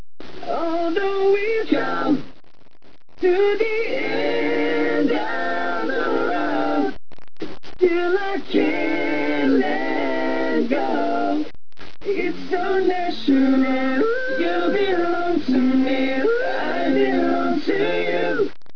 A'Capella version